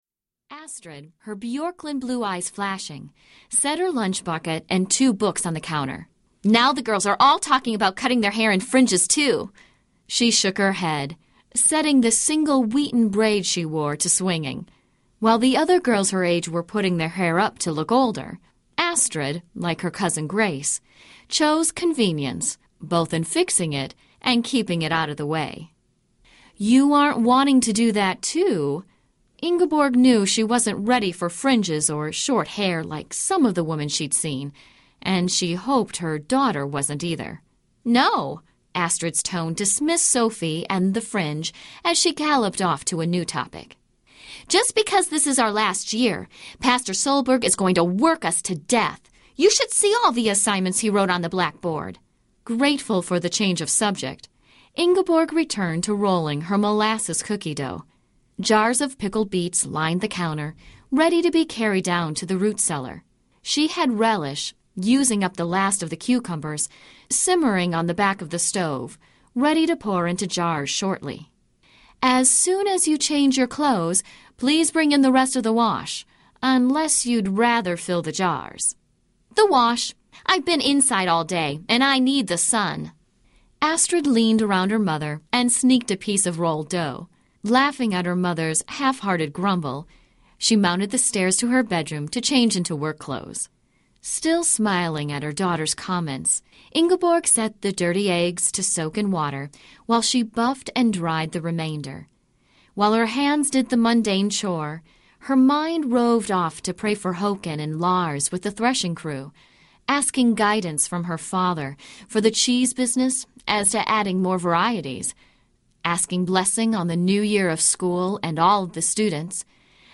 Sophie’s Dilemma (Daughters of Blessing, Book #2) Audiobook
8.5 Hrs. – Abridged